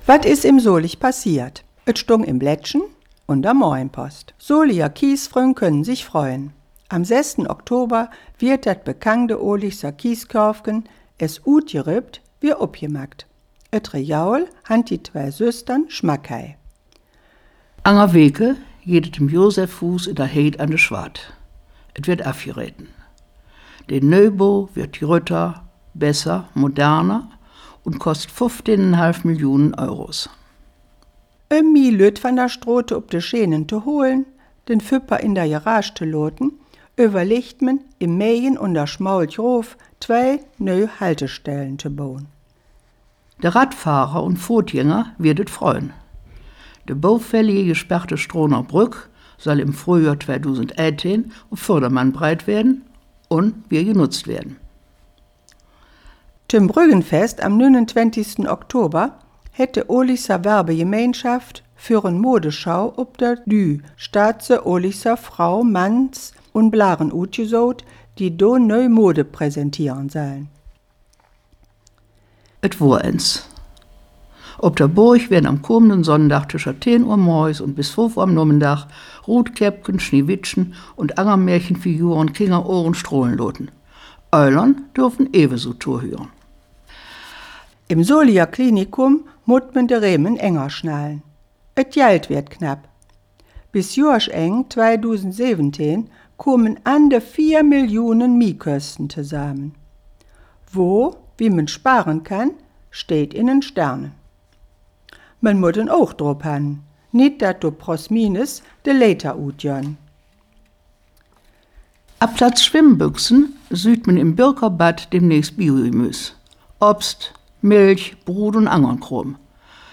Die Folgen der Nachrichten in Solinger Platt aus 2017:
39-KW-Solinger-Platt-News.wav